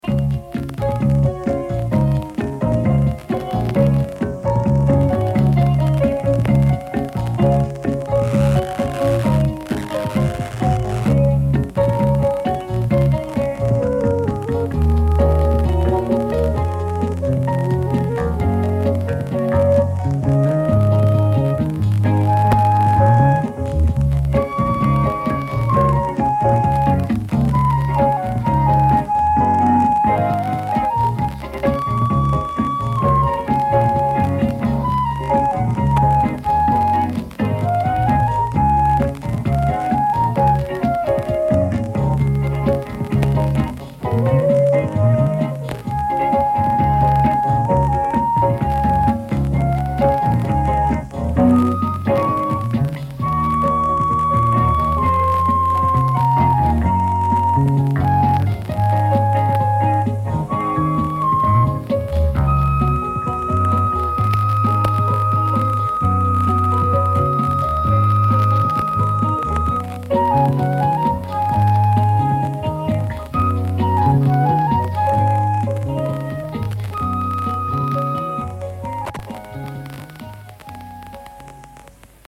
tunnusmelodia (tallenteen äänenlaatu huono